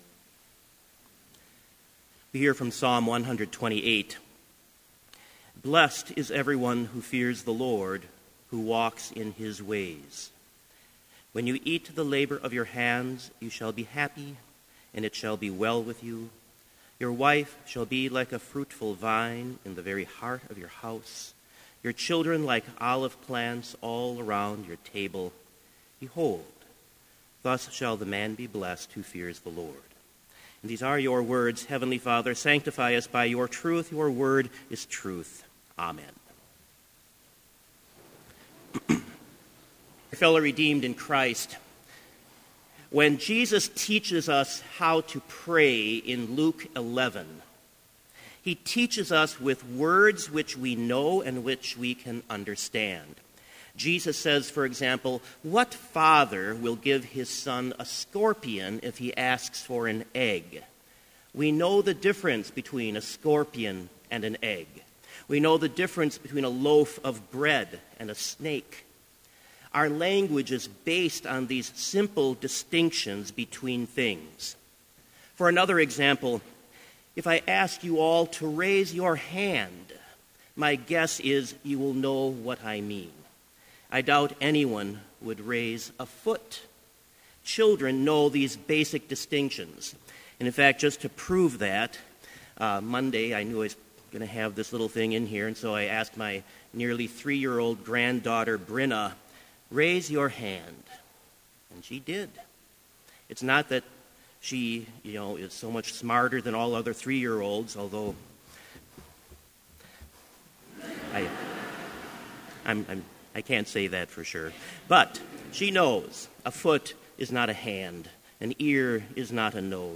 Complete Service
• Hymn 188, vv. 1 & 2, Hear Us Now Our God and Father
• Devotion
This Chapel Service was held in Trinity Chapel at Bethany Lutheran College on Thursday, January 21, 2016, at 10 a.m. Page and hymn numbers are from the Evangelical Lutheran Hymnary.